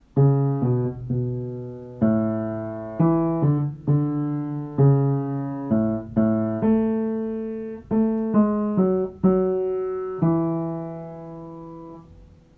BASS: Hymn #130: Be Thou Humble
Audition Key: A major* |
Starting Pitch: C# | Sheet Music
bass-hymn-130-a-major-m4a